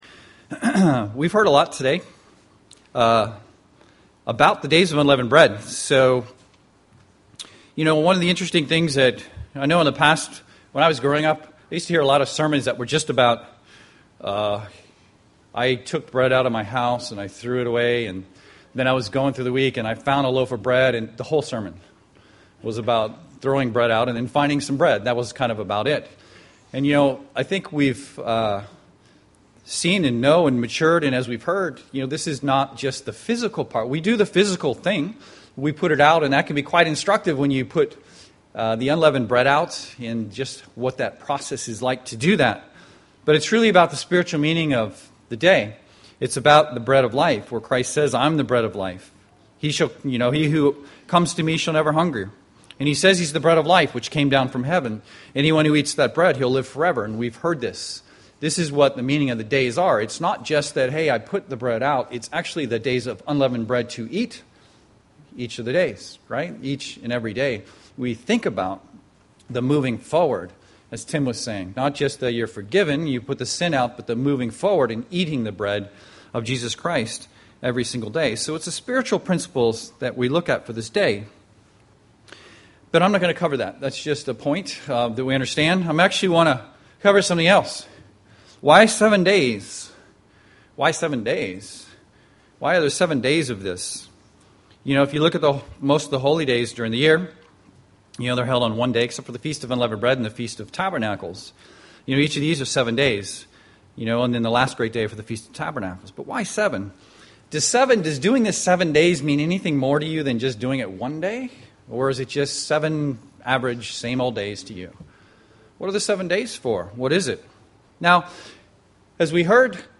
Print UCG Sermon Studying the bible?
Given in Seattle, WA